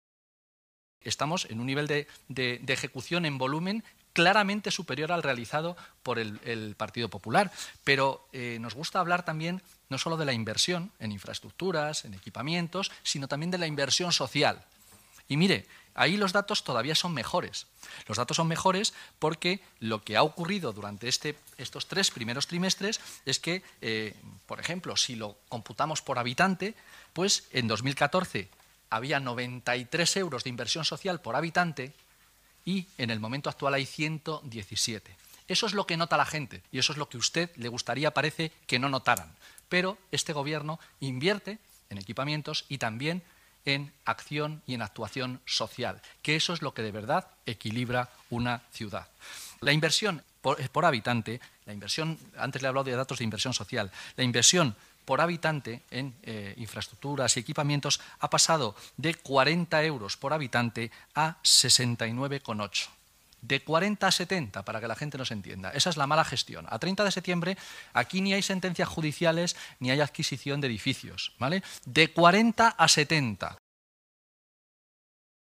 El concejal del Área de Economía y Hacienda, Carlos Sánchez Mato, señala el nivel de ejecución presupuestaria señalando especialmente los buenos datos de la inversión social: